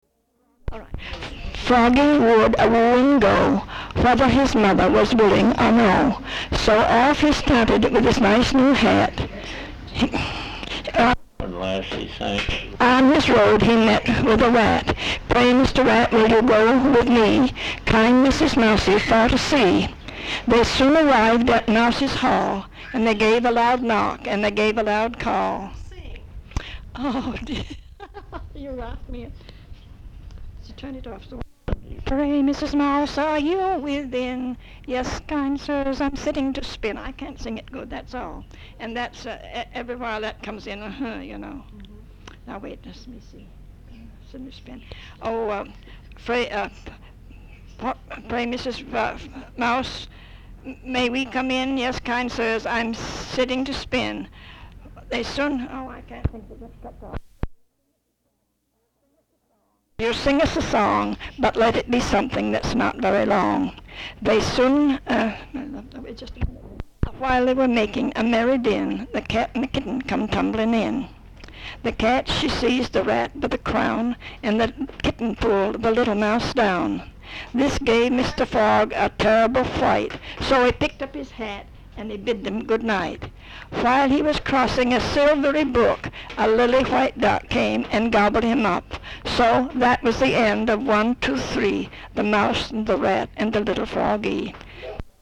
Folk songs, English--Vermont (LCSH)
sound tape reel (analog)